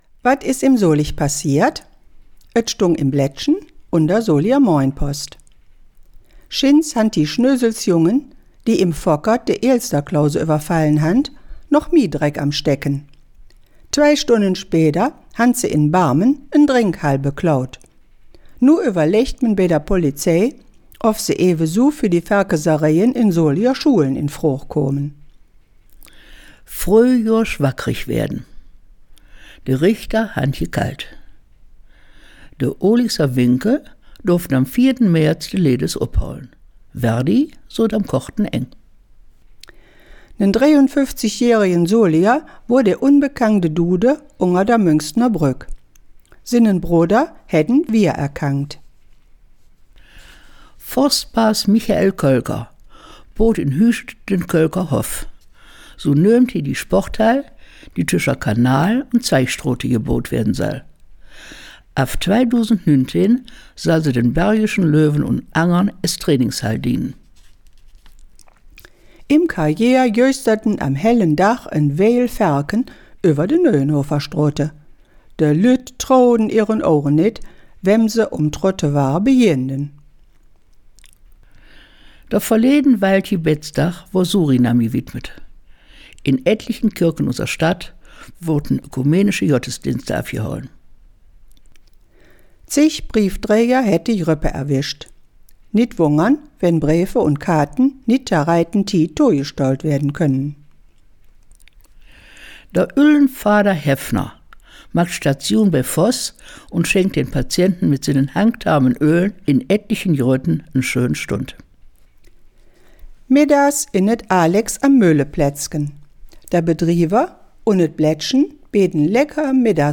Der Nachrichten-Rückblick auf die KW10
soliger-platt-news-18kw10.mp3